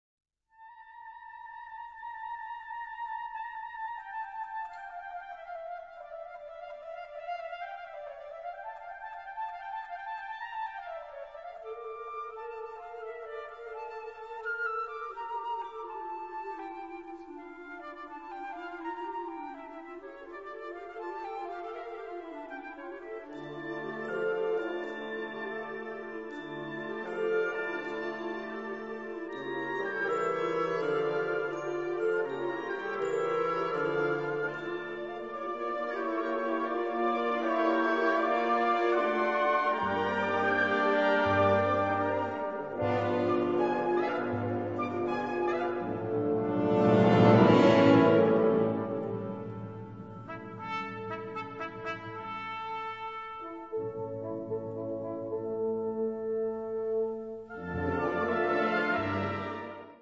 Categorie Harmonie/Fanfare/Brass-orkest
Subcategorie Muziek uit opera, ballet
Bezetting Ha (harmonieorkest)